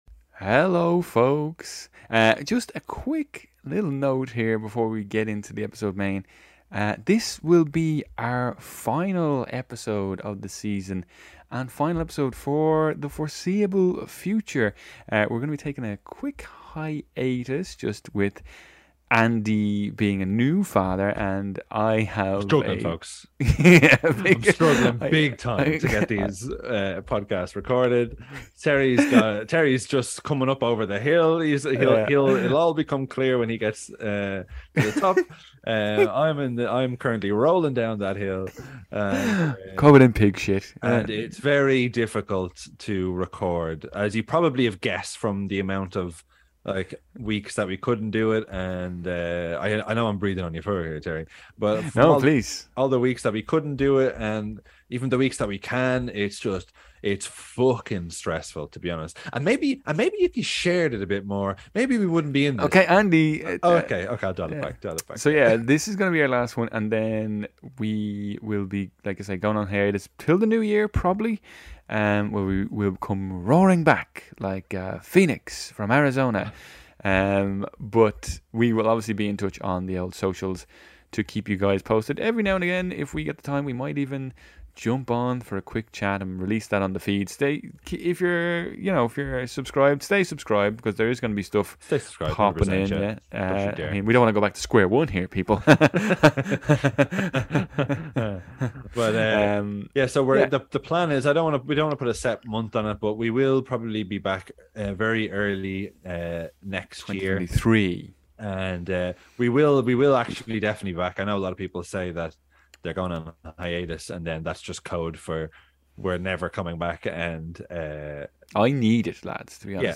We have a great laugh throughout, hope you enjoy it!